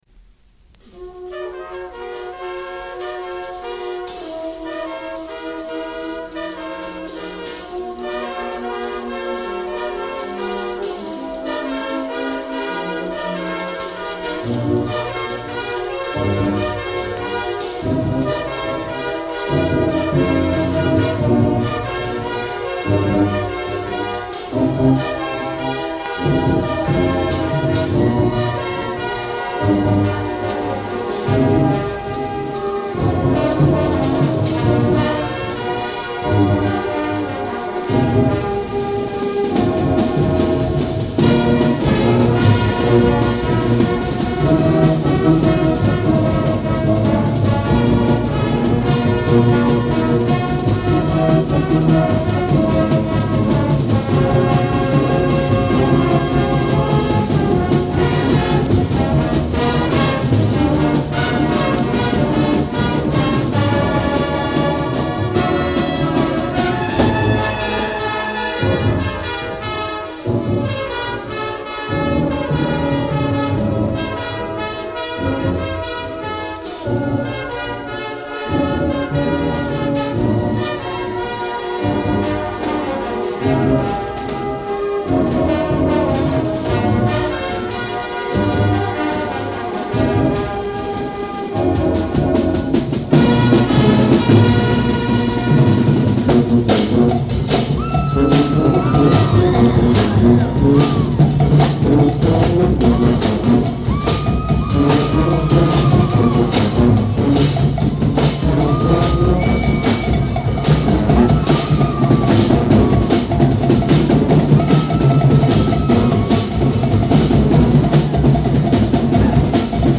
1975 Marching Falcons In Concert Program